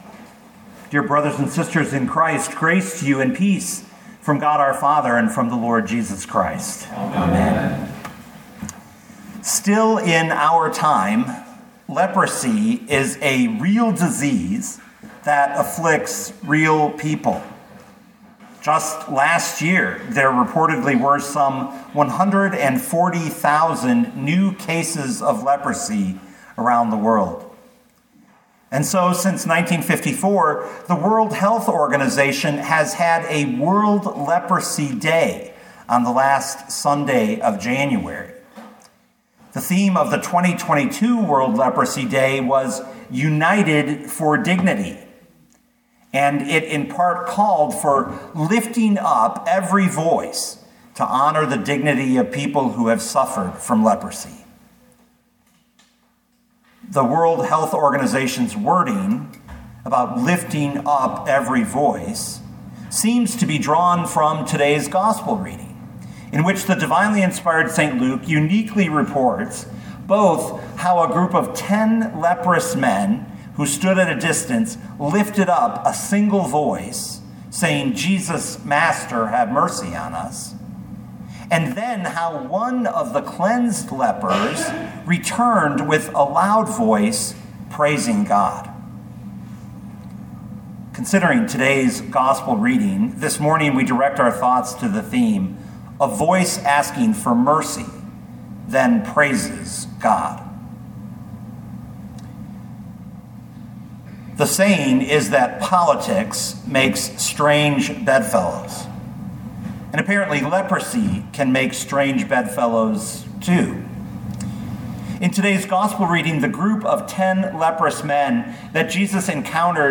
a-voice-asking-for-mercy-then-praises-god.mp3